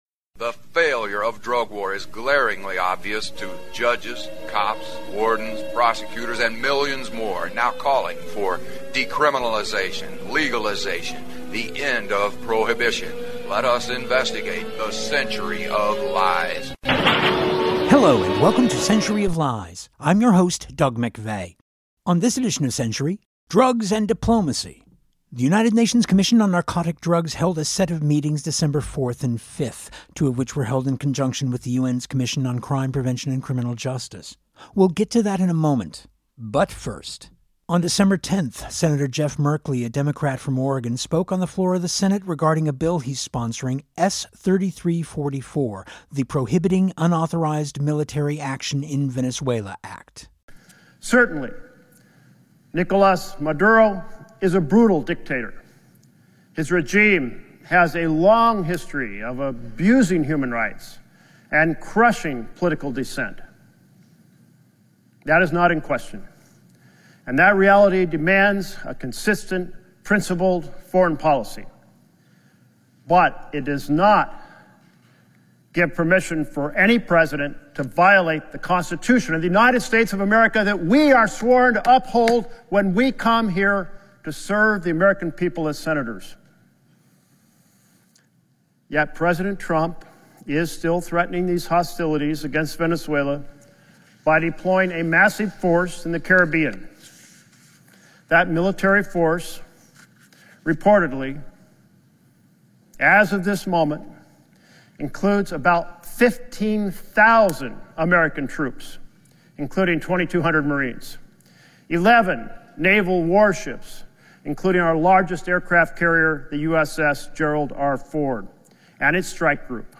The United Nations Commission on Narcotic Drugs held its 69th session this year from March 9-13 in Vienna, Austria. This week’s edition of Century is part one of our coverage, featuring addresses by Colombian President Gustavo ...